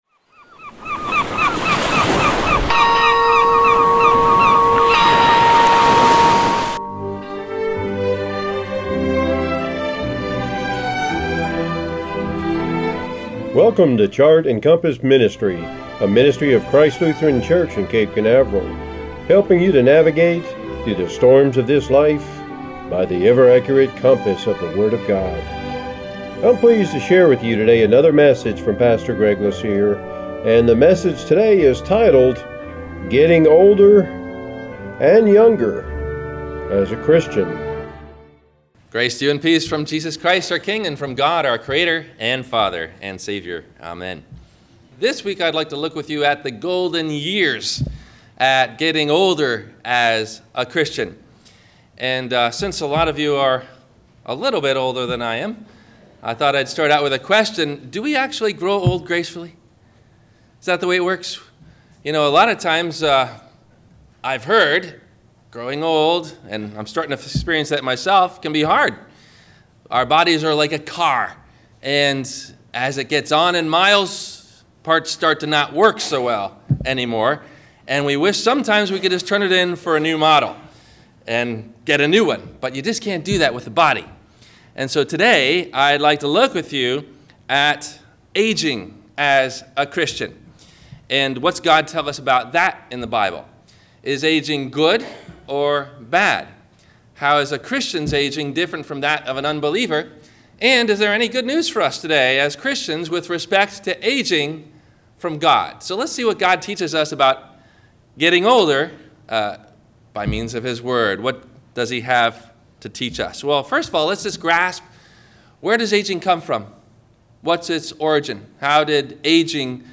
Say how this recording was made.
No Questions asked before the Sermon message: